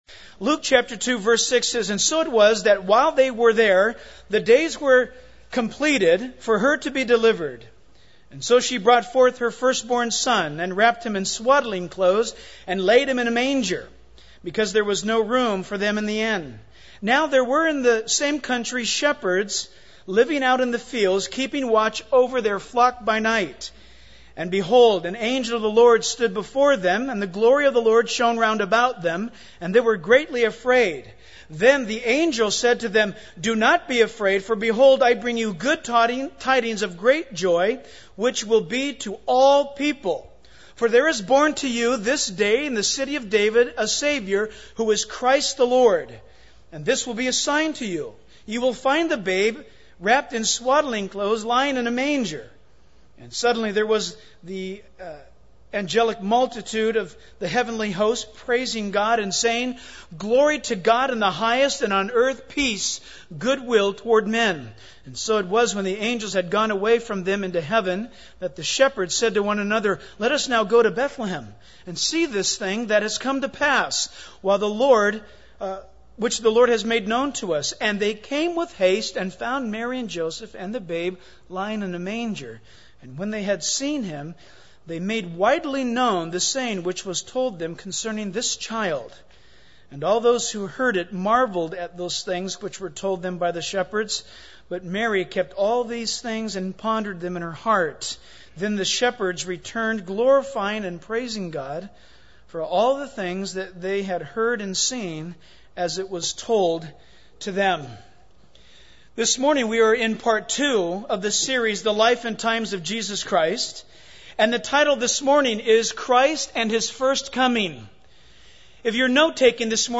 In this sermon, the preacher emphasizes the importance of understanding and proclaiming the Word of God. He references Galatians 4:4-5, which speaks of God becoming a human.